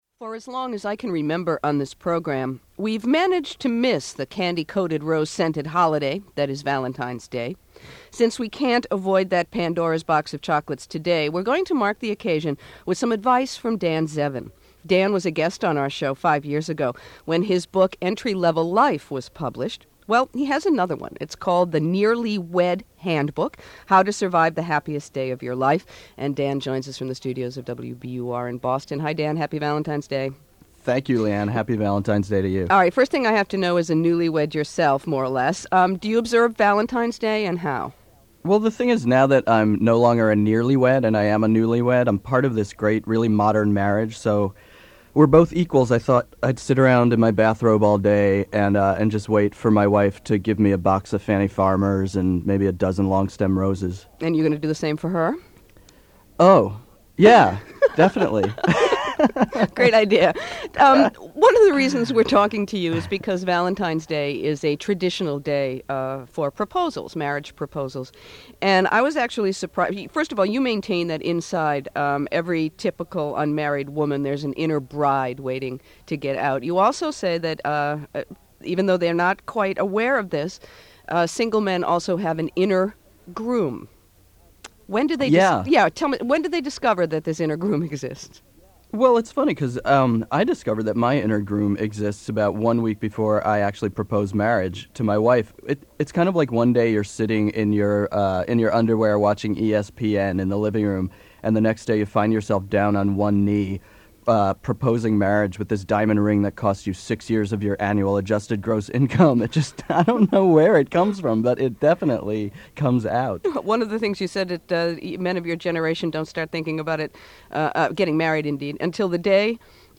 NPR Interview